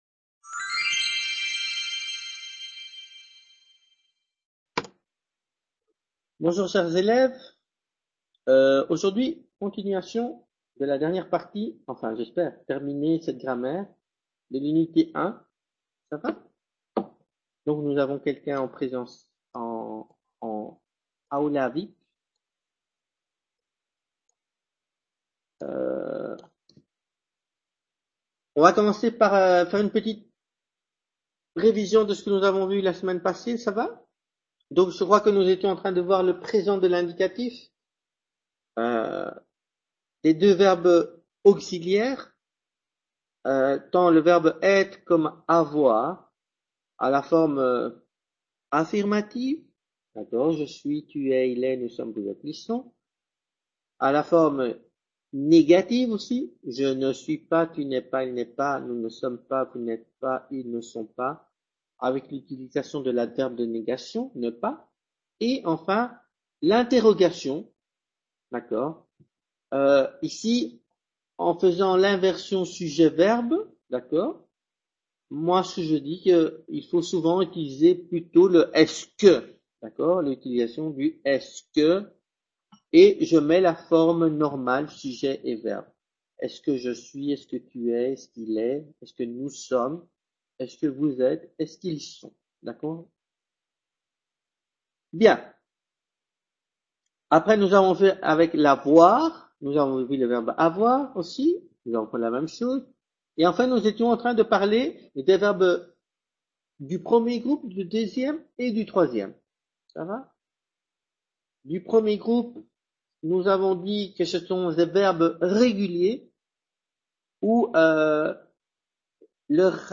Clase de Francés Nivel Intermedio 3/12/2015 | Repositorio Digital